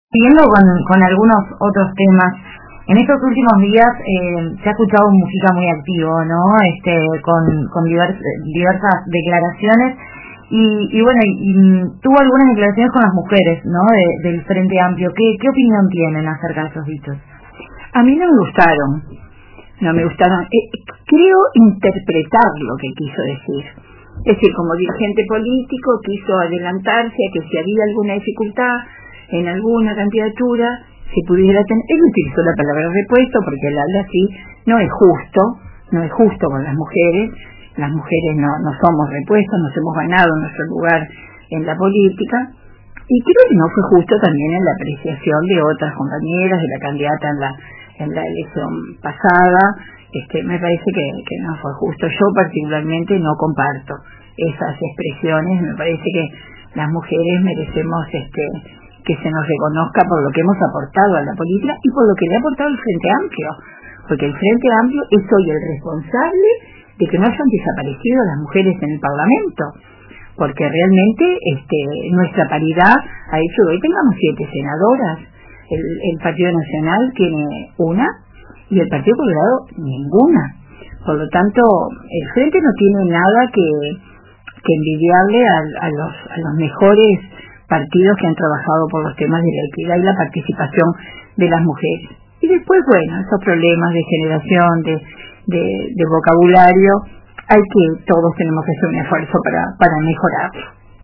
La senadora Liliam Kechichián, integrante del sector Seregnistas, participó del programa “La Tarde de RBC” y expresó su descontento con las declaraciones del expresidente José Mujica.